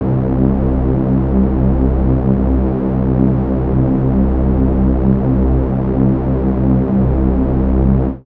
TS Synth Bass_3.wav